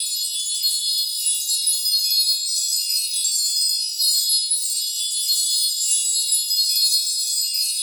magic_sparkle_gem_loop_02.wav